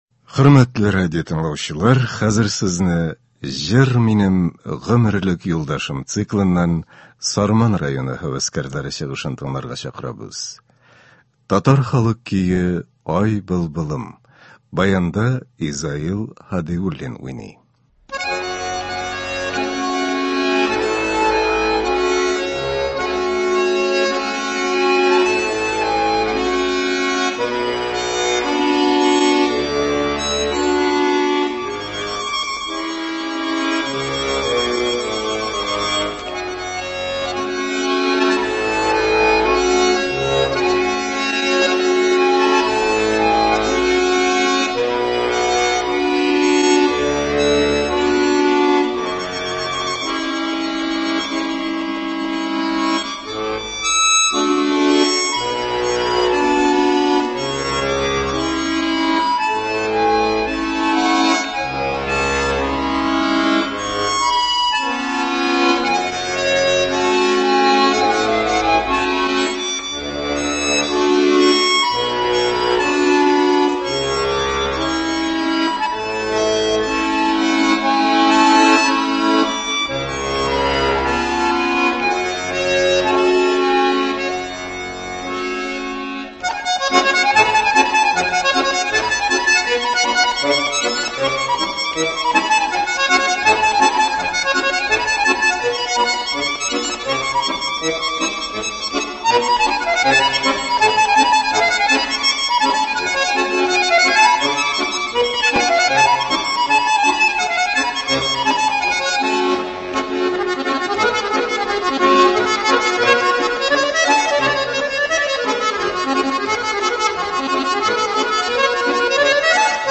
Концерт (20.06.22)